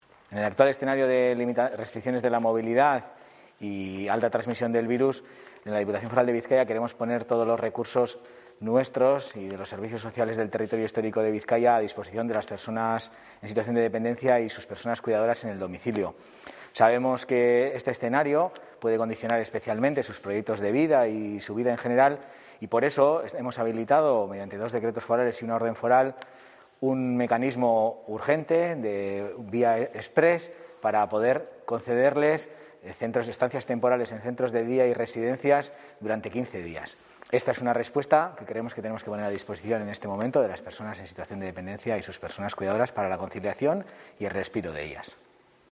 Sergio Murillo, Diputado de Acción Social